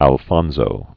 (ăl-fŏnzō, äl-fōnsō) 1886-1941.